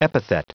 Prononciation du mot epithet en anglais (fichier audio)
Prononciation du mot : epithet